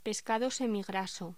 Locución: Pescado semigraso
voz